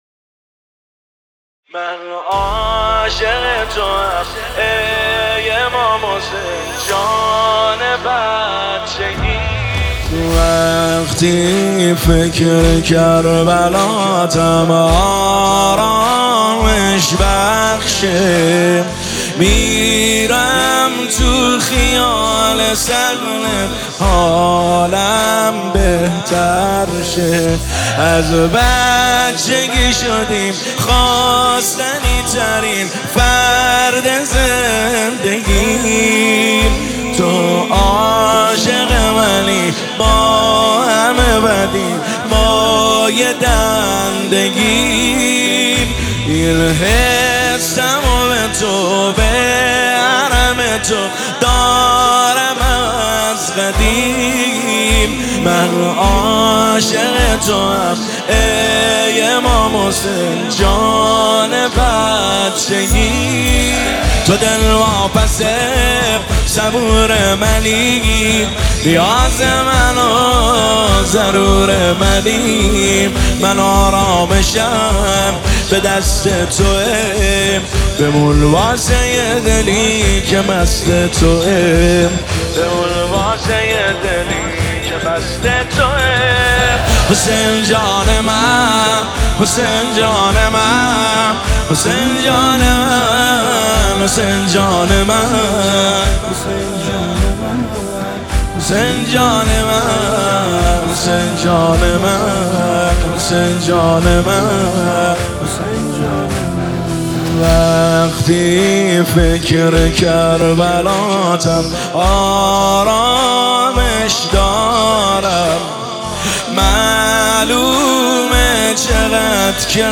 نماهنگ استودیویی احساسی زیبا